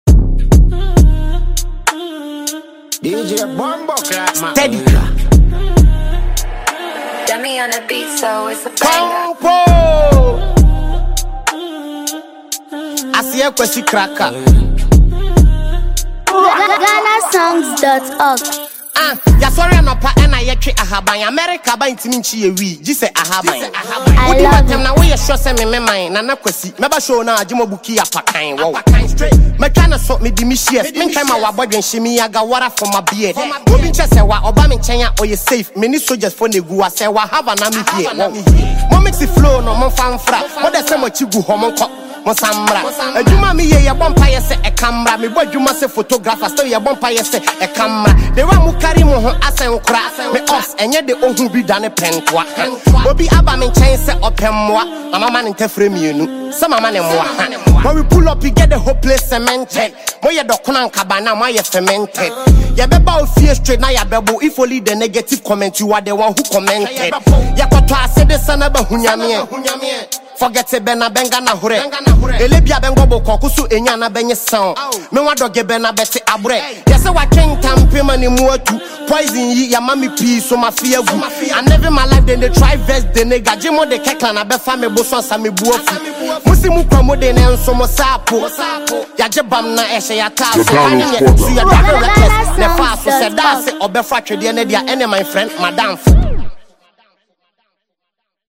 Ghanaian rapper
gritty drill and hip-hop feel